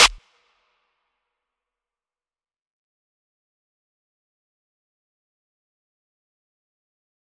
DMV3_Clap 5.wav